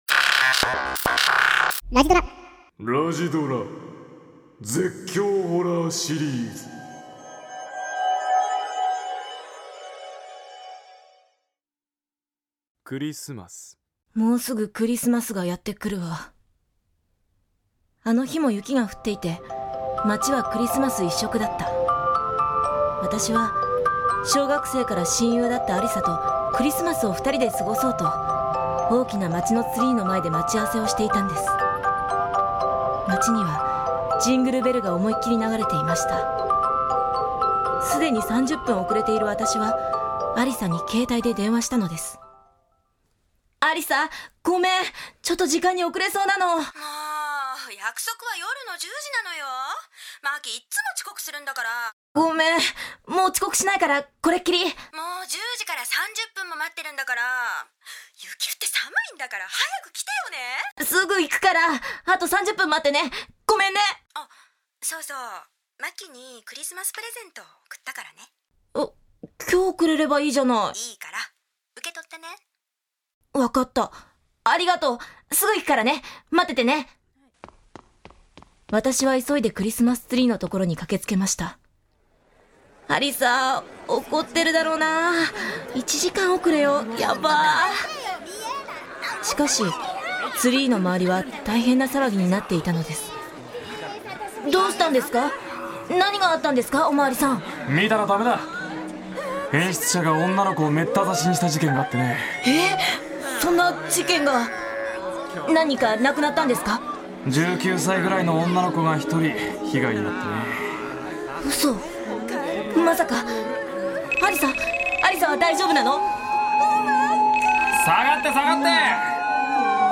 [オーディオブック] ラジドラ 絶叫ホラーシリーズ
携帯でしか聞けない恐怖のラジオドラマ、「ラジドラ絶叫ホラーシリーズ」が登場！